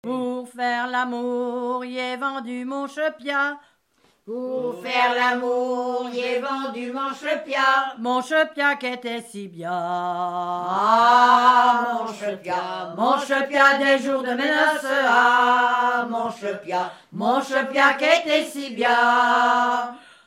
Genre énumérative Propriété et enquête Informateur(s
Pièce musicale inédite